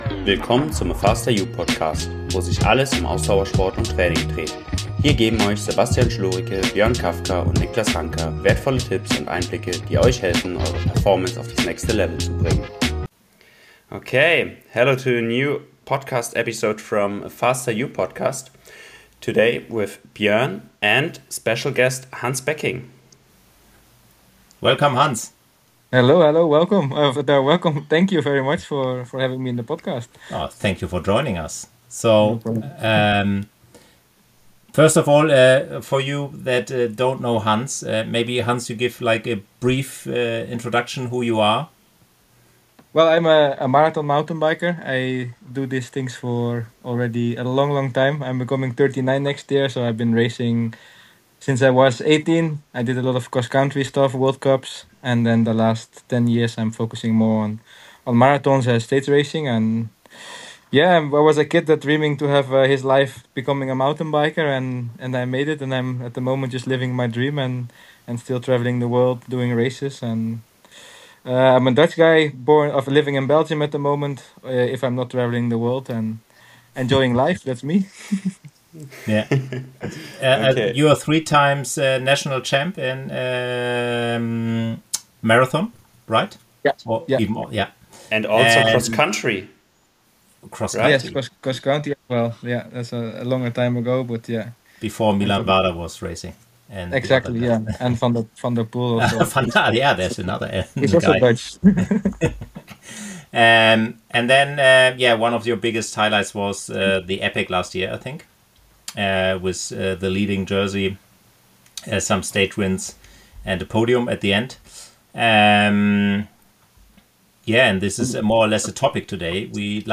In this episode, we talk to professional mountain biker Hans Becking about the legendary Cape Epic, one of the toughest stage races in the world. Hans gives us exclusive insights into preparation, race tactics, and the mental challenge of riding for eight days under extreme conditions.